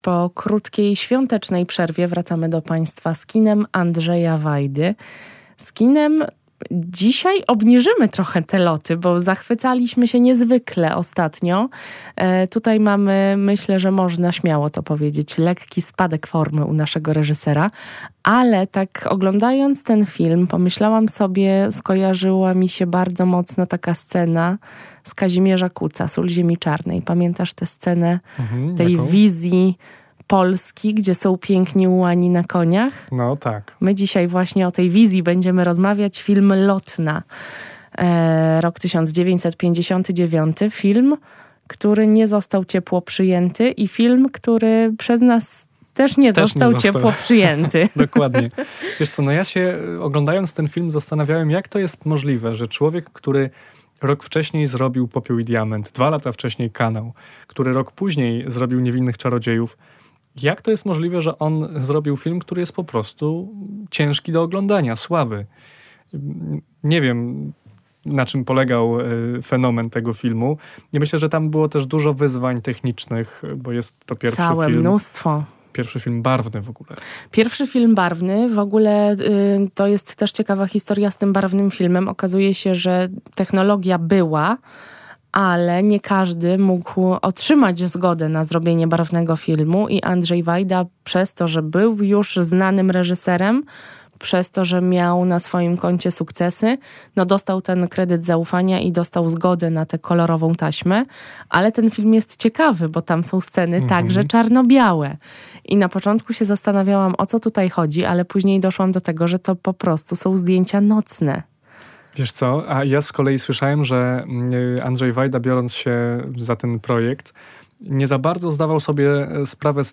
Kolejna rozmowa o twórczości Andrzeja Wajdy.